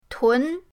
tun2.mp3